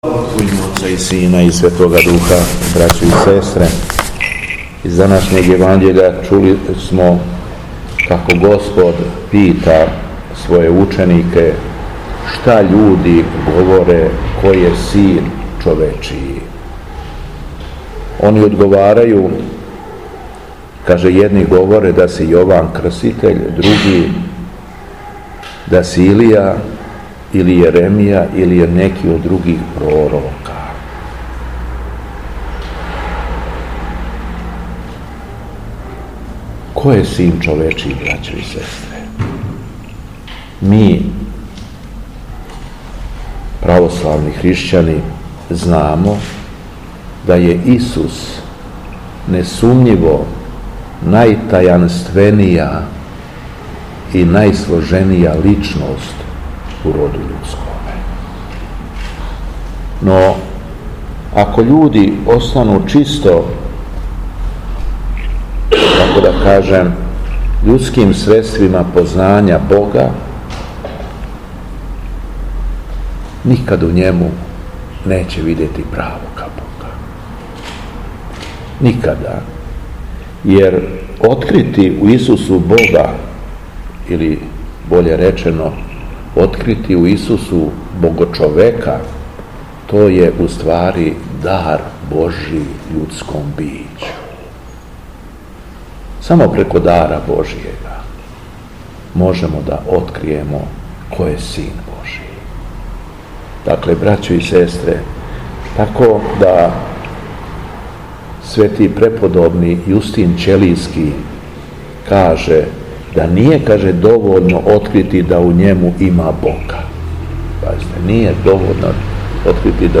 Беседа Његовог Преосвештенства Епископа шумадијског г. Јована
После прочитаног јеванђелског зачала преосвећени владика Јован се обратио верном народу беседом: